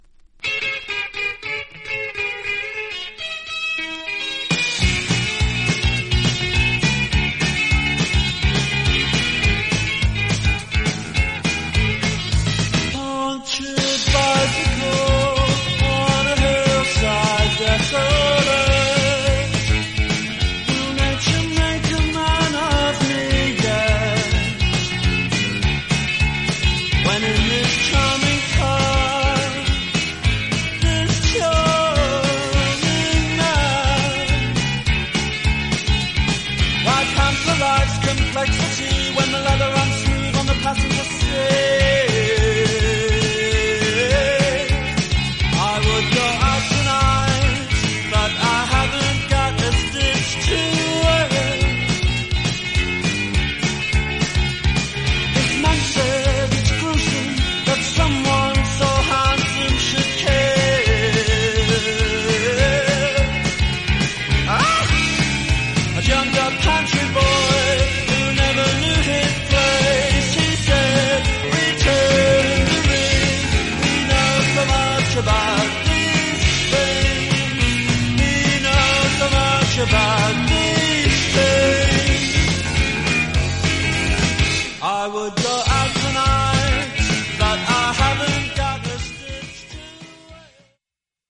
盤面薄いスレが僅かにありますが音に影響ありません。
実際のレコードからのサンプル↓ 試聴はこちら： サンプル≪mp3≫